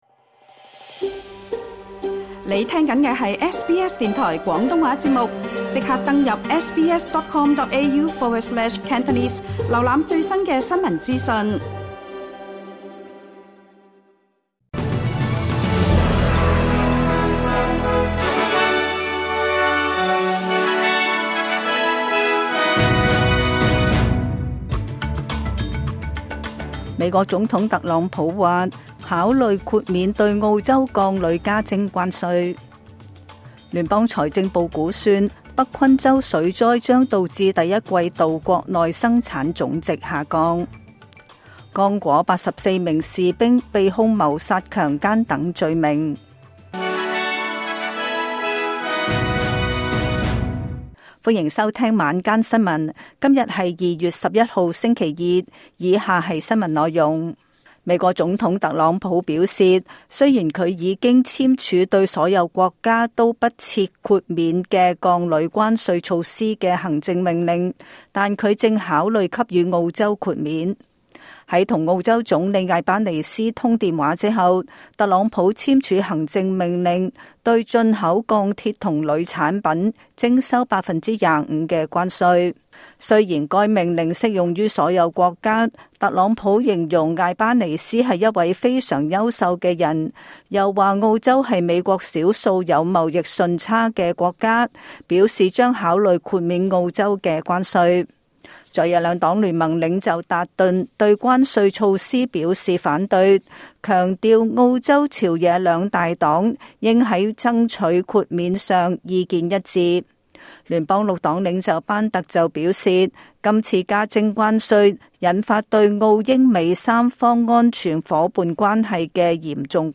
SBS 晚間新聞（2025 年 2月 11日） Play 08:49 SBS廣東話晚間新聞 SBS廣東話節目 View Podcast Series 下載 SBS Audio 應用程式 其他收聽方法 Apple Podcasts  YouTube  Spotify  Download (8.08MB)  請收聽本台為大家準備的每日重點新聞簡報。